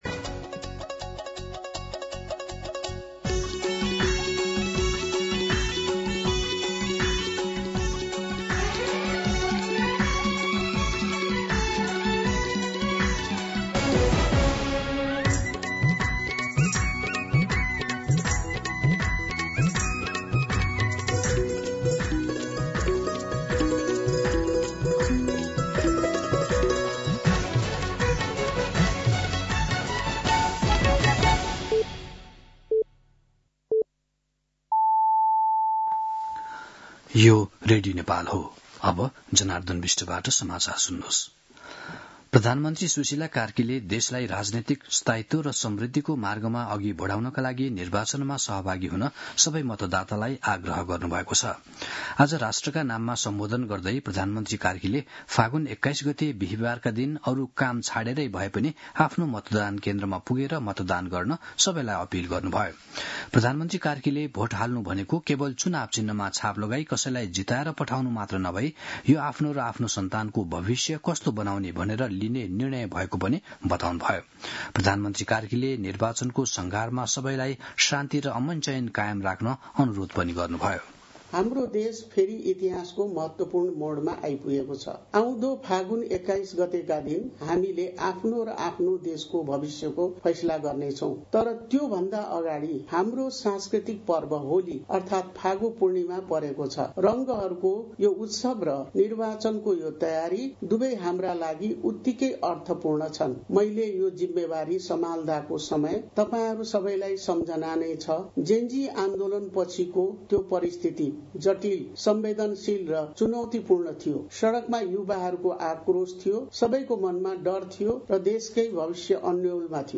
दिउँसो १ बजेको नेपाली समाचार : १८ फागुन , २०८२
1pm-News-11-18.mp3